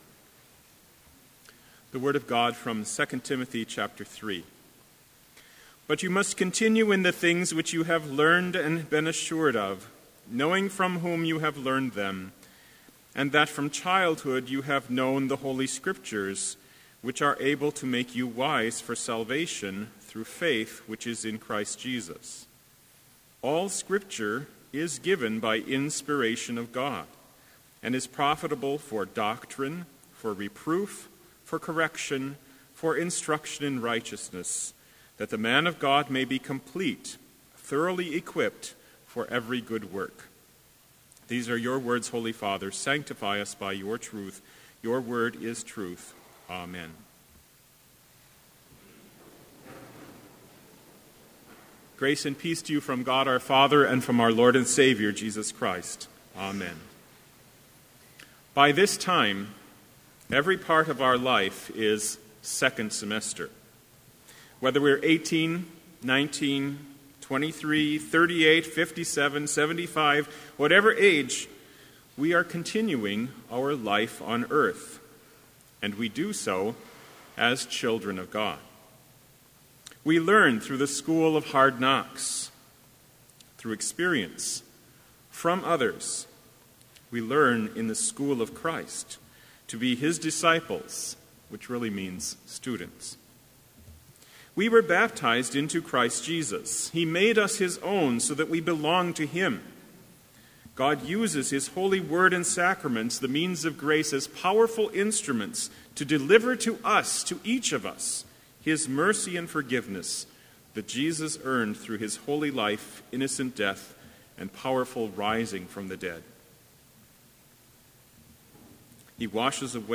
Complete Service
This Chapel Service was held in Trinity Chapel at Bethany Lutheran College on Tuesday, January 12, 2016, at 10 a.m. Page and hymn numbers are from the Evangelical Lutheran Hymnary.